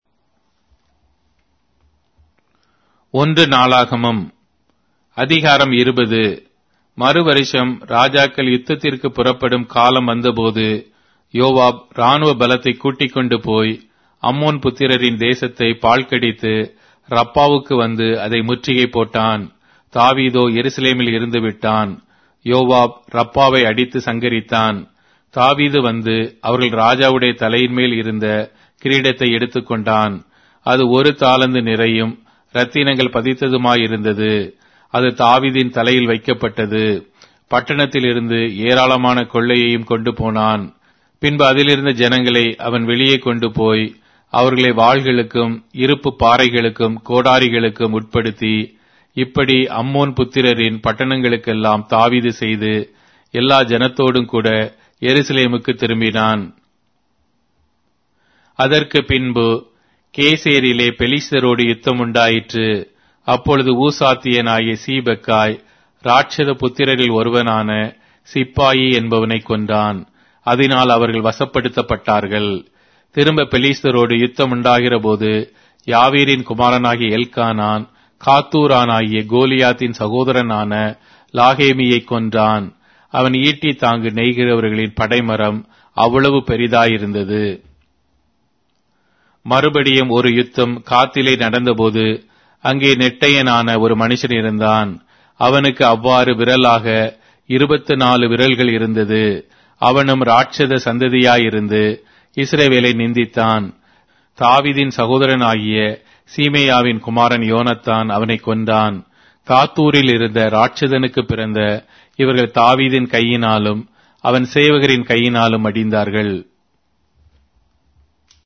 Tamil Audio Bible - 1-Chronicles 2 in Nlv bible version